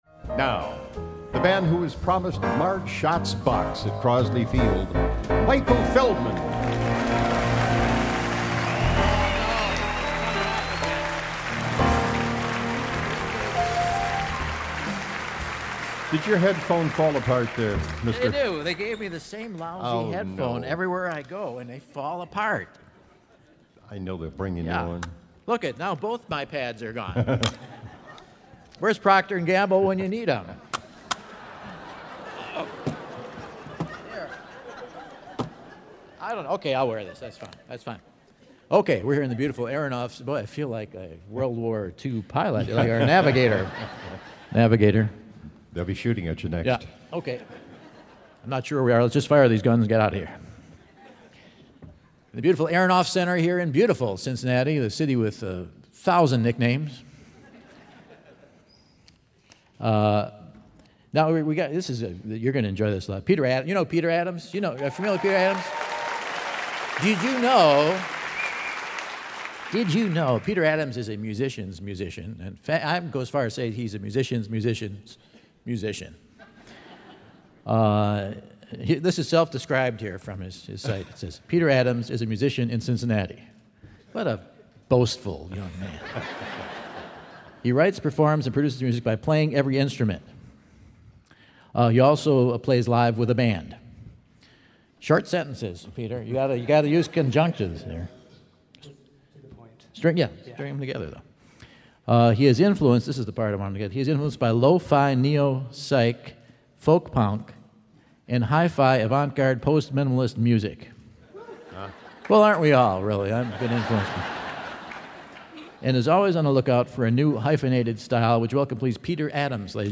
good-natured multi-instrumental, non-genre-defining fun